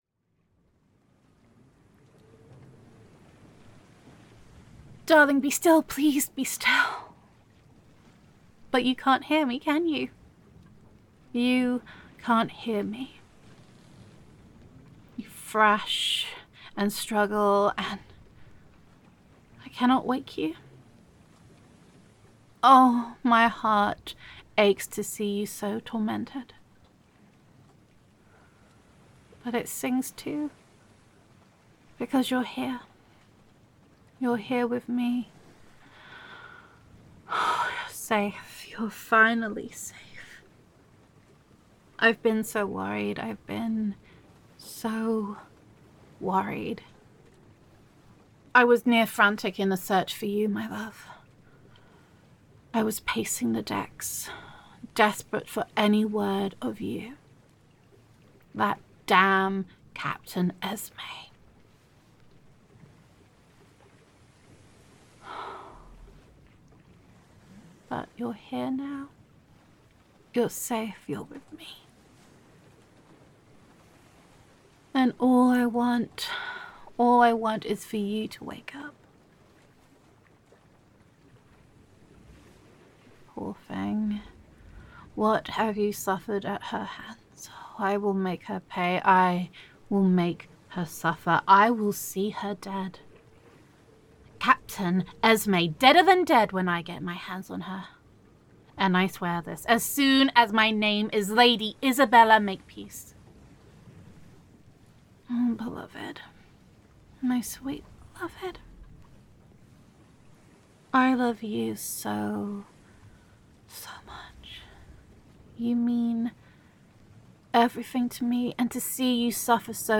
[F4A]
[Pirate Roleplay]
[Creaking Pirate Ship]